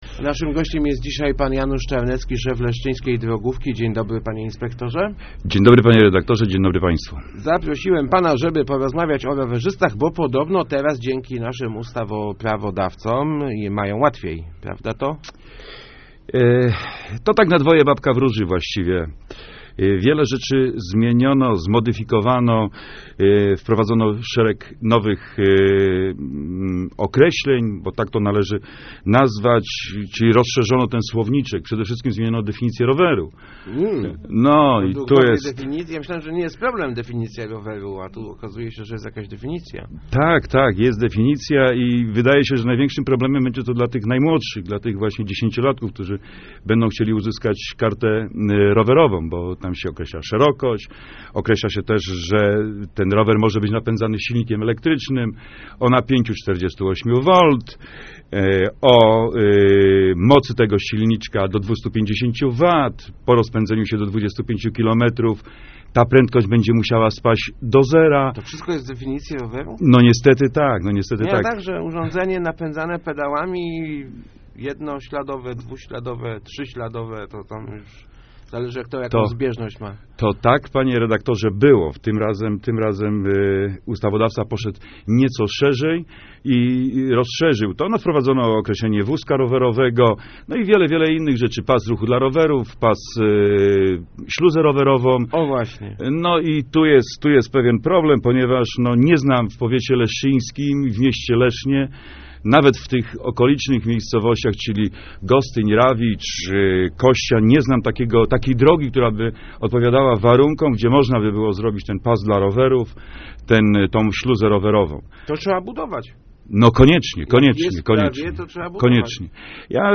Start arrow Rozmowy Elki arrow Czy rowerzyści mają łatwiej?